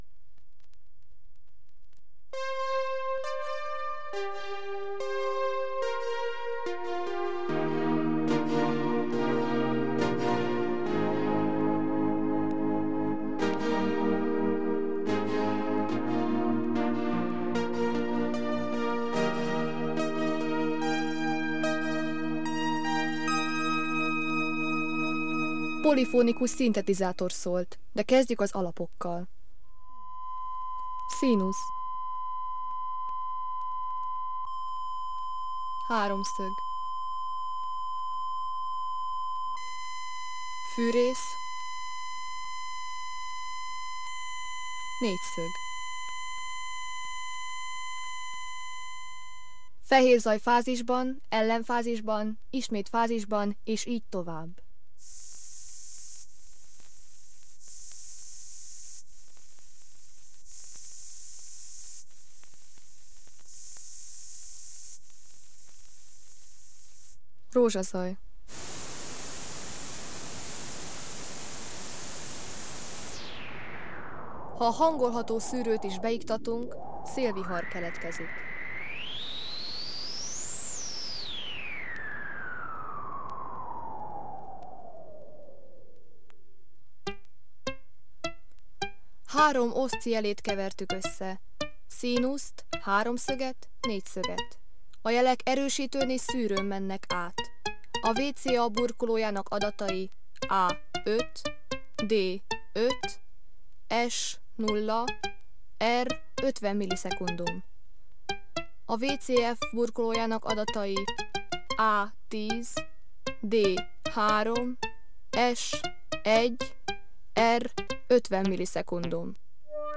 Adathordozó Vinyl kislemez ( 17 cm ), 45-ös percenkénti fordulat
Lemezjátszó Akai AP-Q310